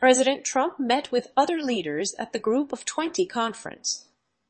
tortoise-tts - (A fork of) a multi-voice TTS system trained with an emphasis on quality
Upload finetuned ljspeech examples